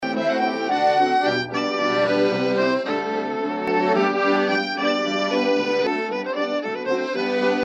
The original recording was made on an Akai 4000DS MkII stereo ¼″ tape deck using Agfa-Gevaert PEM368 tape.
This Akai deck did not have any tape noise suppression facilities (i.e. no Dolby®).
Whole 7×16 bar track ready